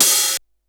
Tr8 Open Hat 03.wav